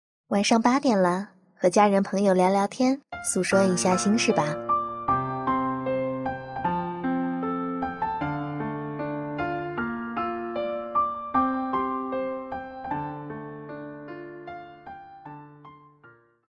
整点报时语音 马思唯/女声，固定音频，时间段为整点-本地
mode String 默认女声，可选择马思唯(msw)
"Tips": "马思唯整点报时语音"